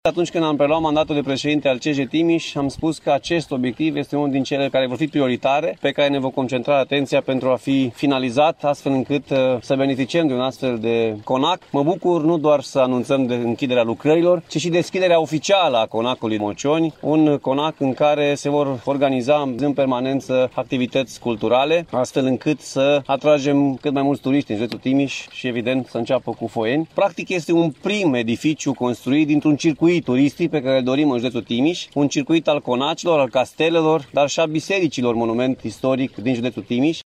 Administrația județeană își dorește ca monumentul să facă parte dintr-un circuit istoric al Timișului, a declarat la inaugurarea lucrărilor de restaurare, președintele Consiliului Județean, Alfred Simonis.
Alfred-Simonis-Conac-Mocioni.mp3